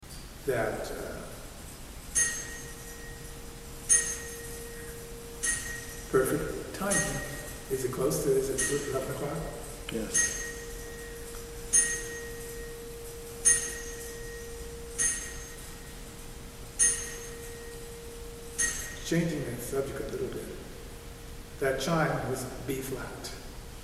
the clock begins to strike 11AM. We sit quietly, listening to the mechanics whir, and the chime ring.
“That chime was b flat.
FEC3-ClockChimes11.mp3